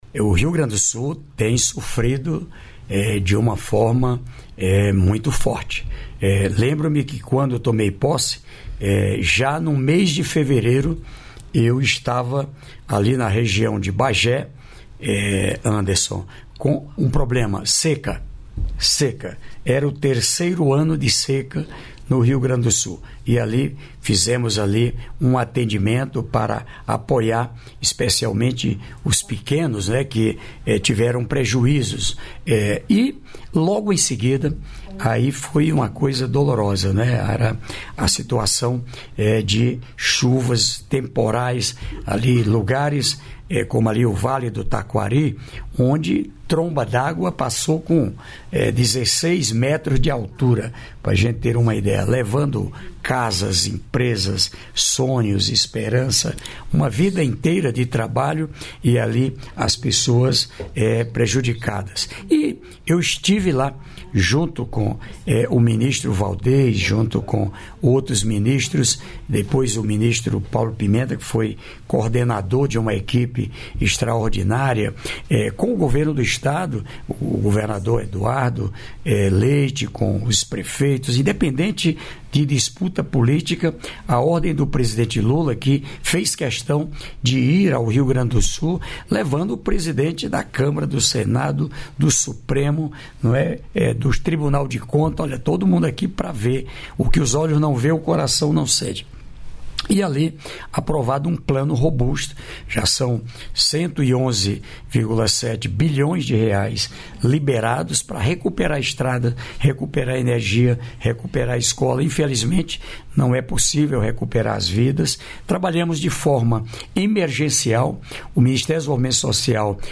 Trecho da participação ministro do Desenvolvimento e Assistência Social, Família e Combate à Fome, Wellington Dias, no programa "Bom Dia, Ministro" desta quarta-feira (07), nos estúdios da EBC em Brasília (DF).